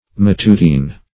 matutine - definition of matutine - synonyms, pronunciation, spelling from Free Dictionary Search Result for " matutine" : The Collaborative International Dictionary of English v.0.48: Matutine \Mat"u*tine\, a. Matutinal.